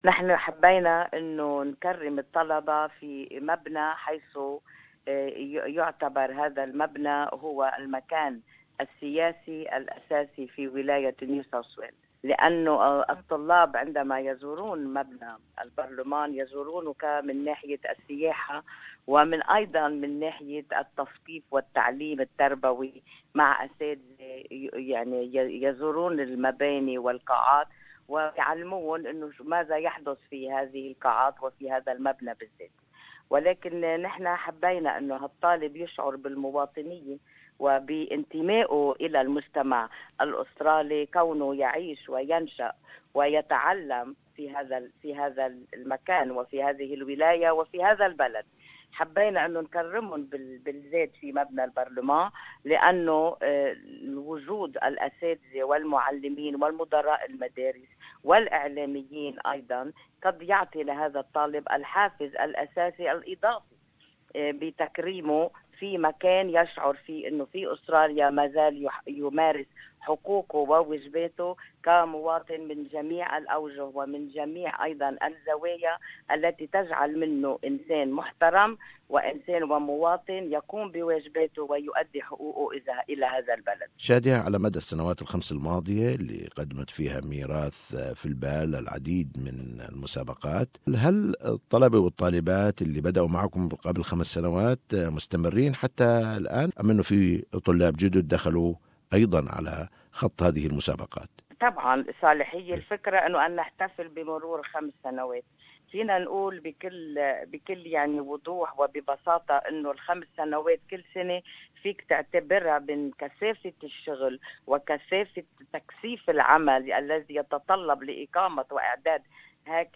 Speaks to SBS Arabic 24 about this event and Mirath in Mind selected artist for 2016 Ziad Rahbani & Georges Khabbaz